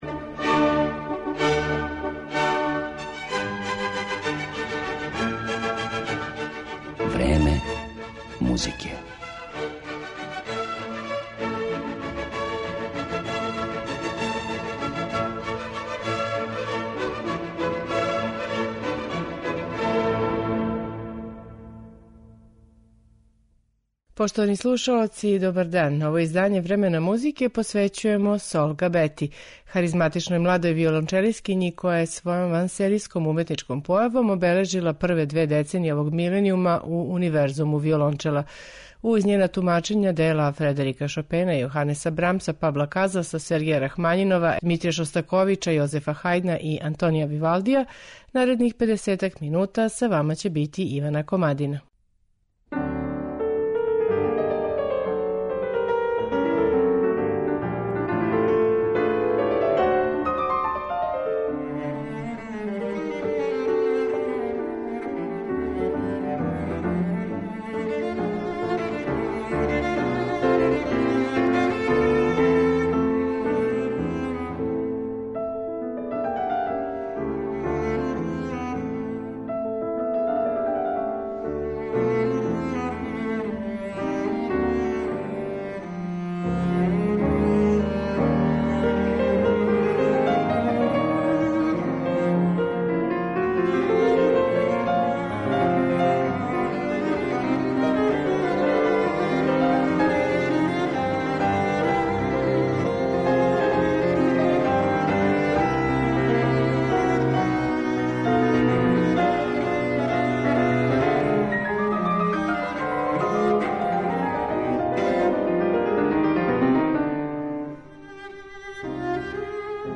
Ово тврди Сол Габета, млада виолончелисткиња, која је својом вансеријском уметничком појавом обележила прве две деценије овог миленијума.